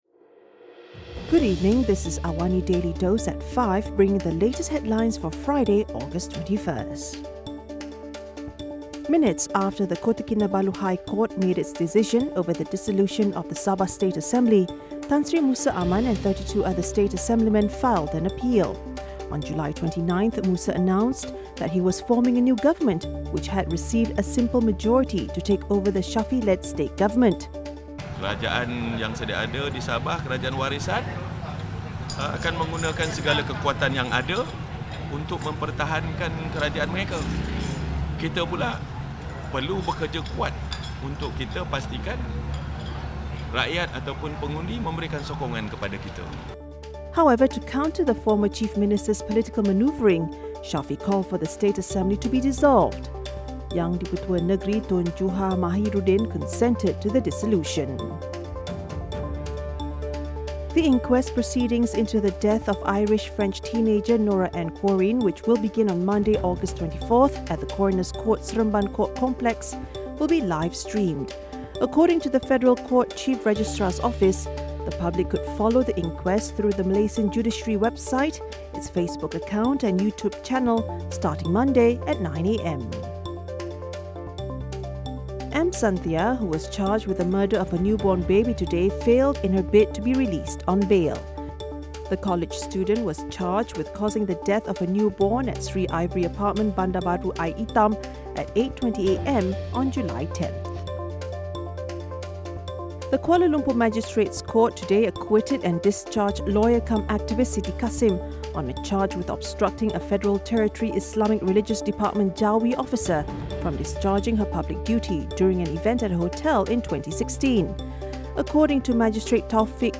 Genres: Business, News